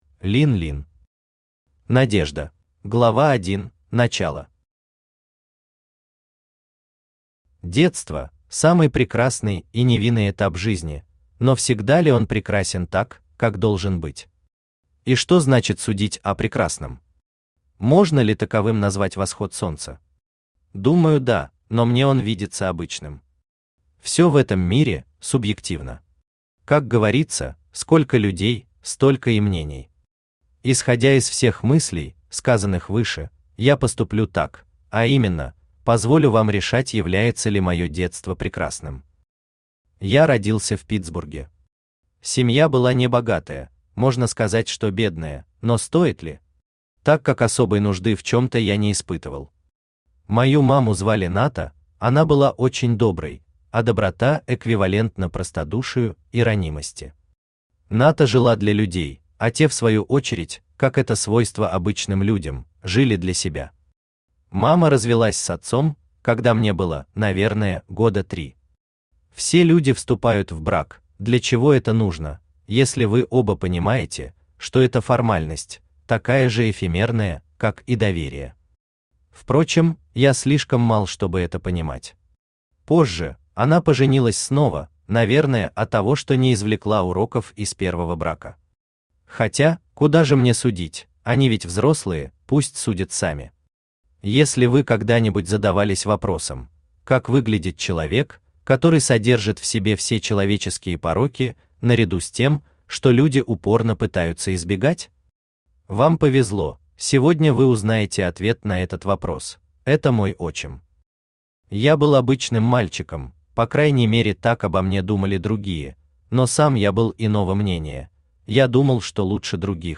Aудиокнига Надежда Автор Lin Lin Читает аудиокнигу Авточтец ЛитРес.